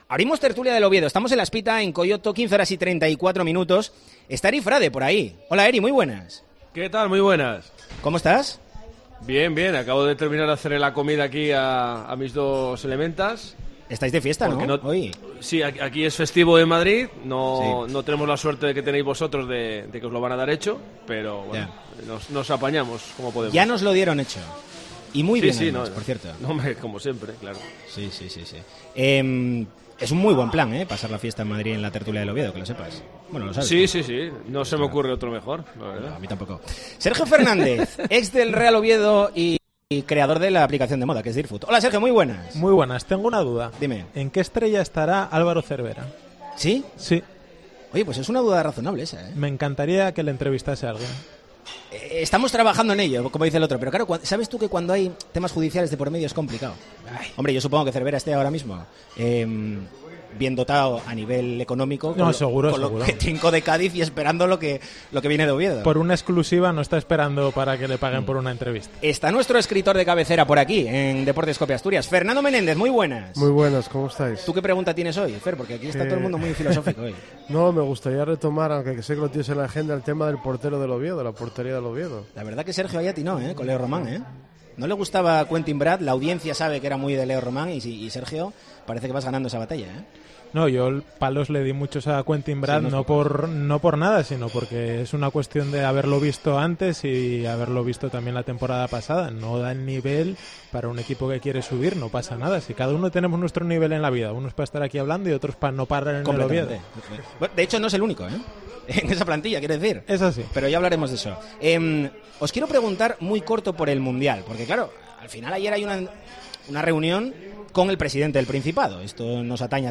'La Tertulia del Oviedo' en Deportes COPE Asturias